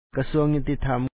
Pronunciation Notes 25
kasúaŋ ñutithám Ministry of Justice